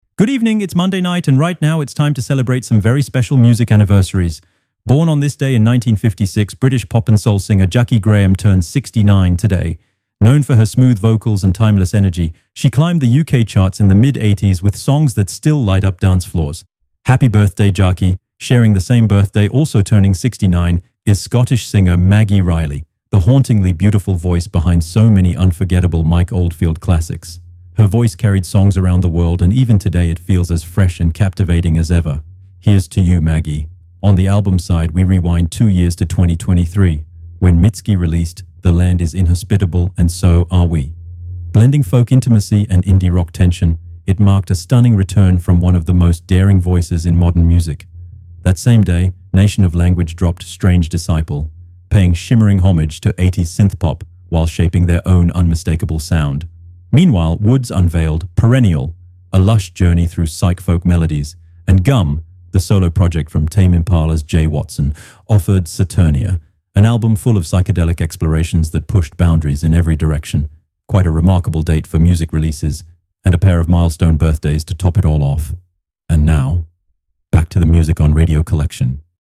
You're listening to the Pop Rock column on Radio Collection, the free, ad-free web radio station that broadcasts the greatest classics and new releases in Hi-Fi quality.
A lively column that mixes memories, anecdotes, and discoveries for a true daily journey through the history of Pop Rock fans' favorite artists, from the 70s to today.